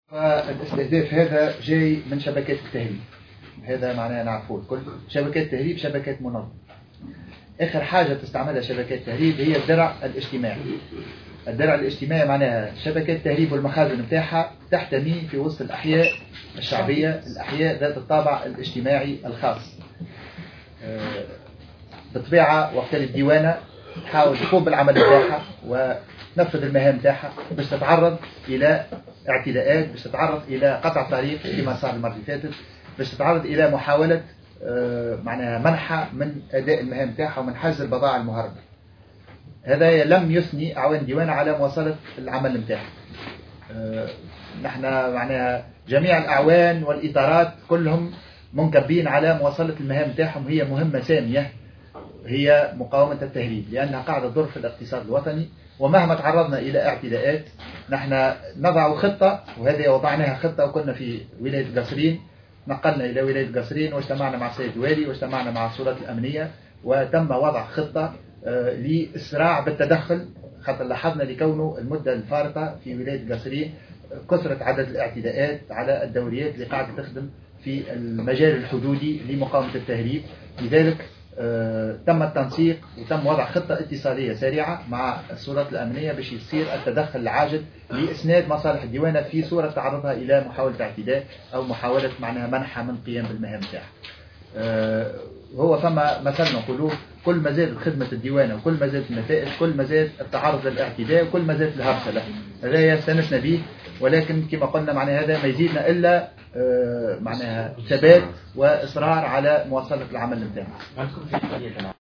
خلال ندوة صحفية عُقدت اليوم السبت.